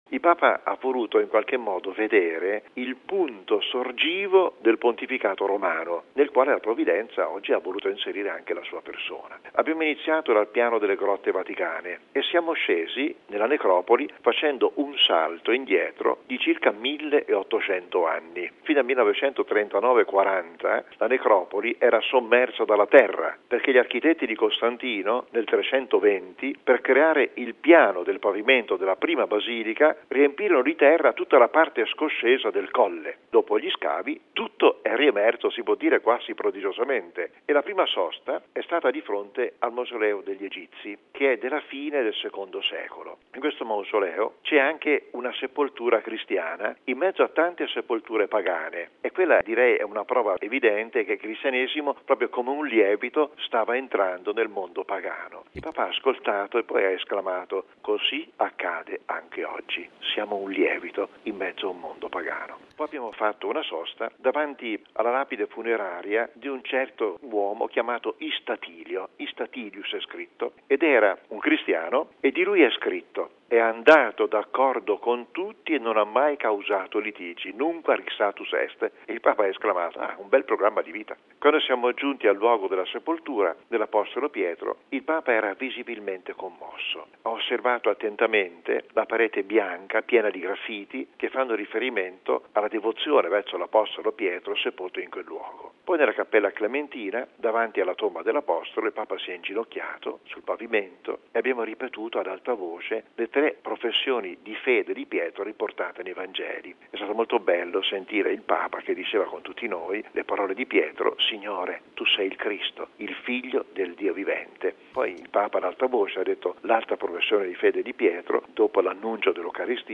A descrivere la visita, e riferire alcuni dei commenti del Papa, è il cardinale Angelo Comastri, arciprete della Basilica Vaticana. L’intervista